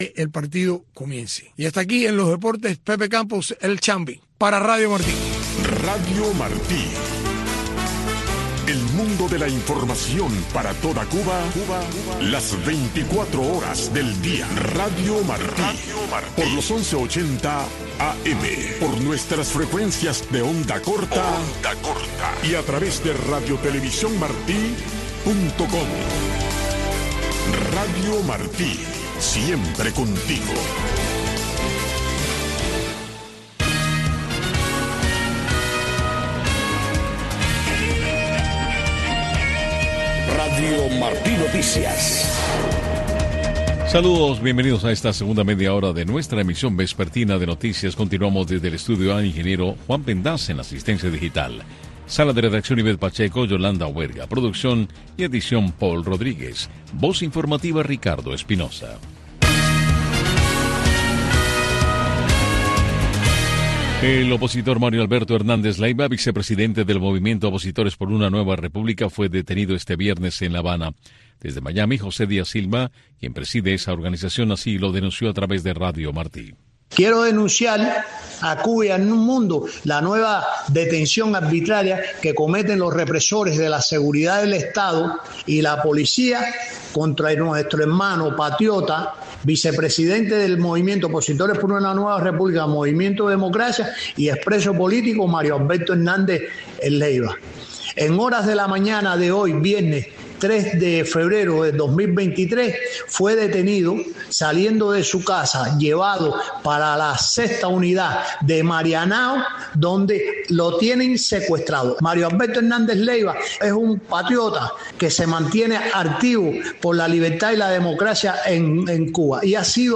Noticiero de Radio Martí 5:00 PM | Segunda media hora